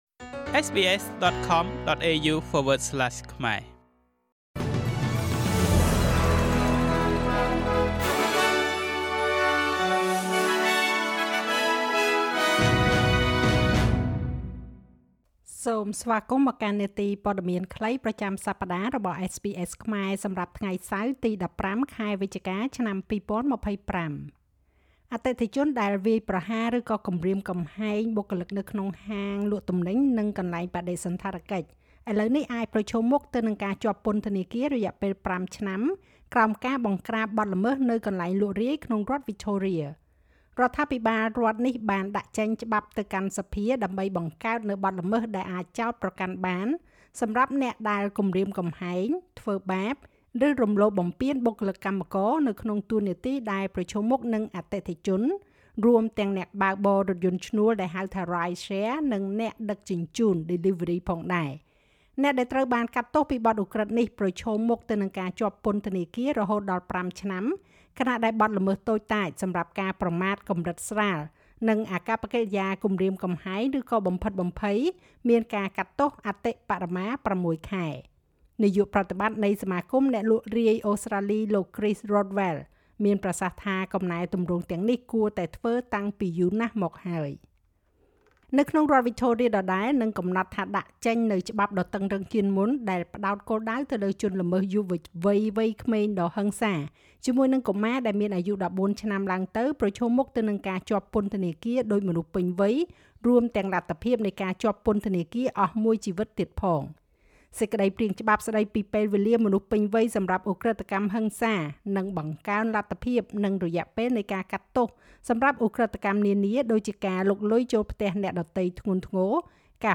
នាទីព័ត៌មានខ្លីប្រចាំសប្តាហ៍របស់SBSខ្មែរ សម្រាប់ថ្ងៃសៅរ៍ ទី១៥ ខែវិច្ឆិកា ឆ្នាំ២០២៥